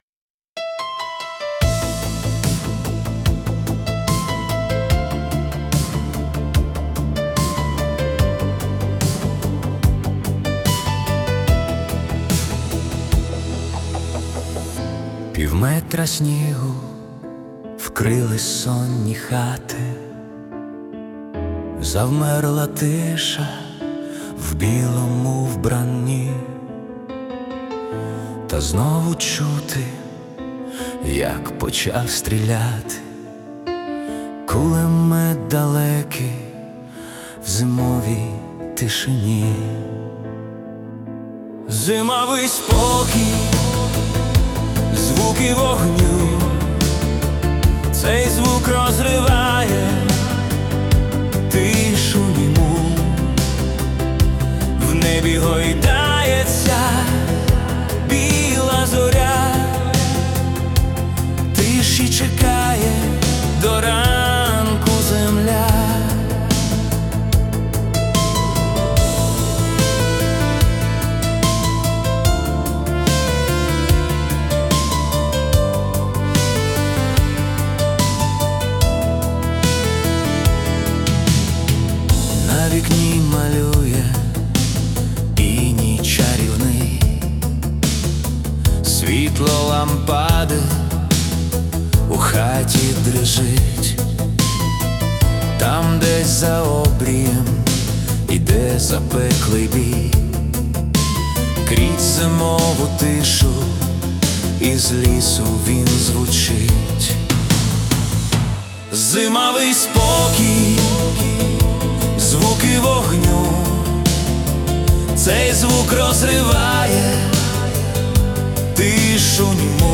🎵 Жанр: Синт-поп 80-х